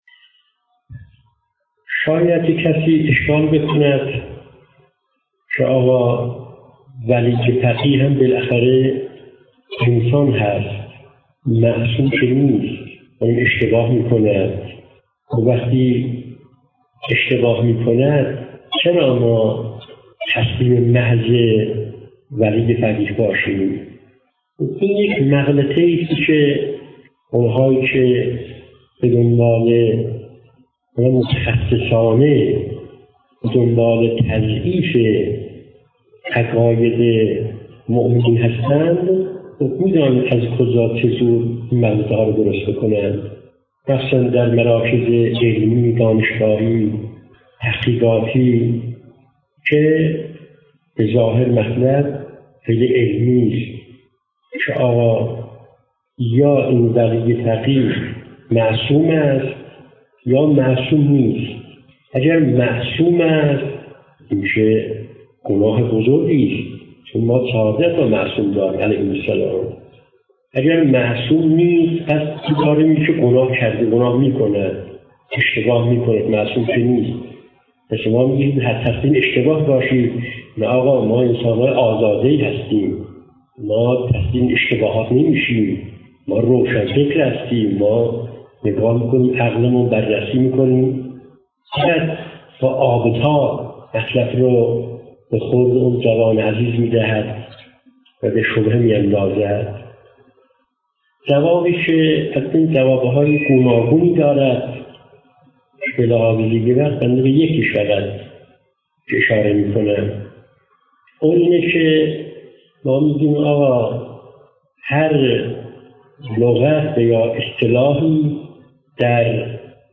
🎧 قطعه صوتی | گوش دهیم. 🎙ولایت الهی| پاسخ به شبهه عدم عصمت ولی فقیه 📌شبهه: شاید کسی اشکال بکند که ولی فقیه هم بالاخره انسان است.